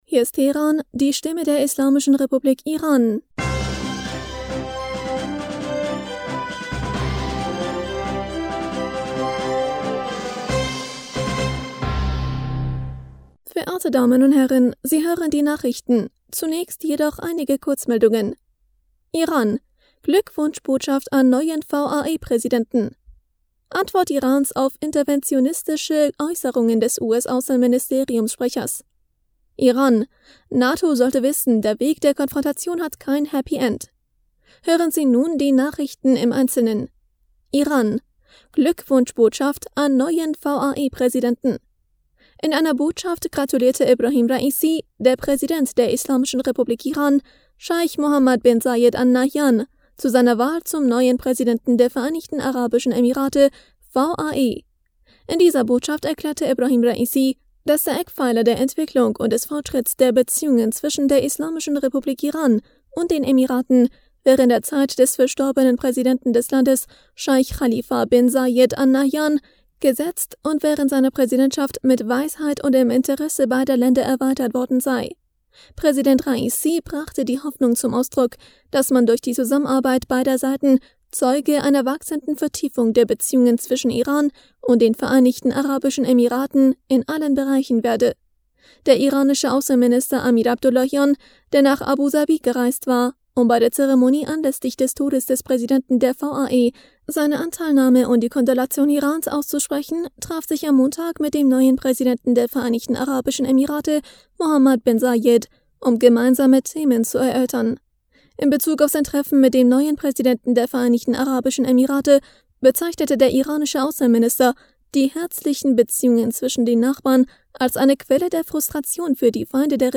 Nachrichten vom 17. Mai 2022